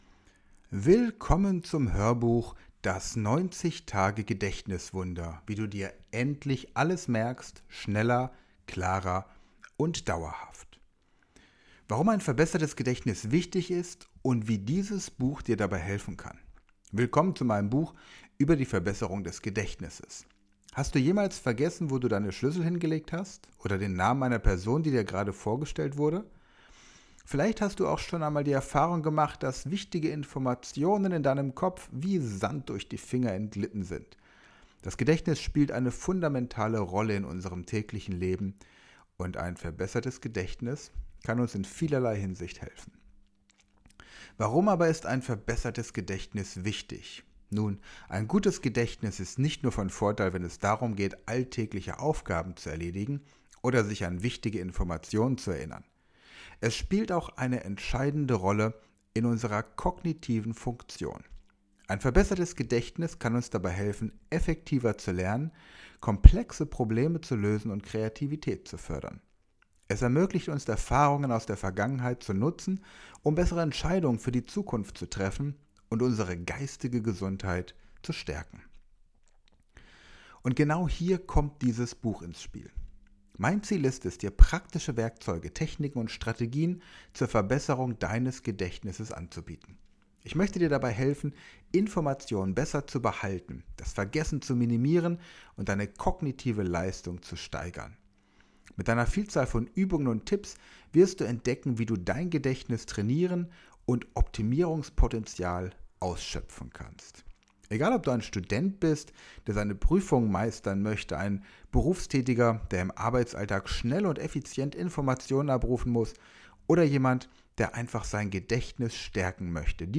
Hoerbuch.mp3